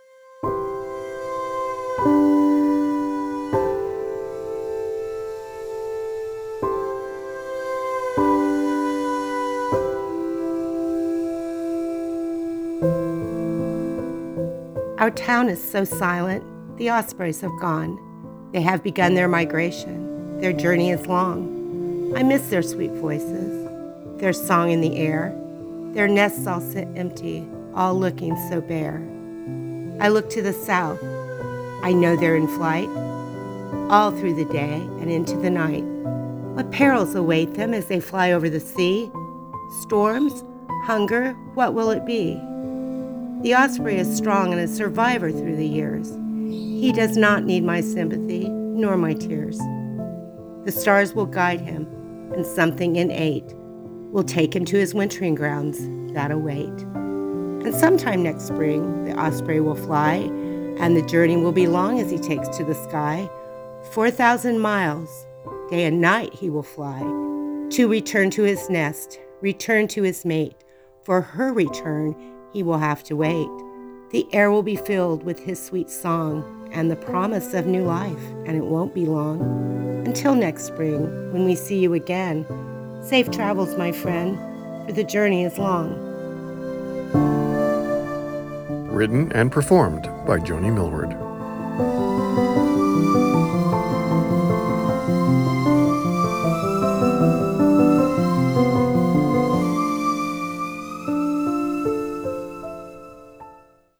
Osprey Poem.wav